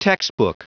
Prononciation du mot textbook en anglais (fichier audio)
Prononciation du mot : textbook